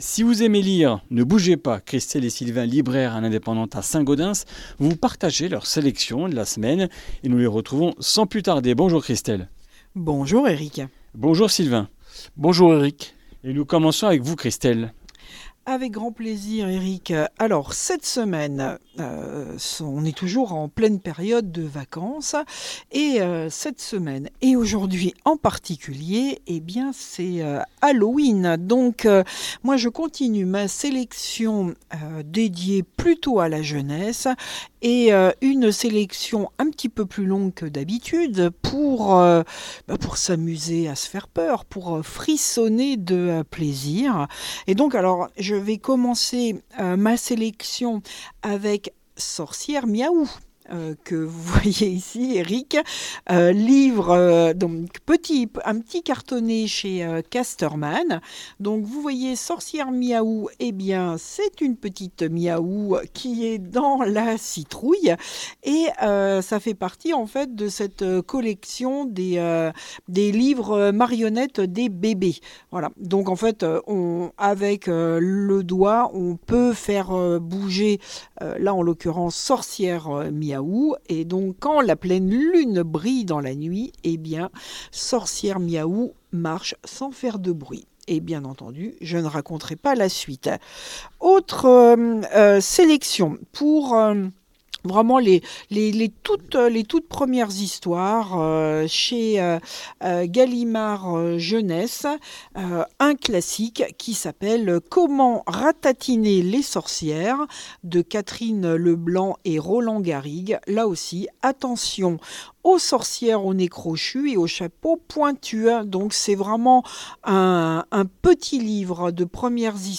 Comminges Interviews du 31 oct.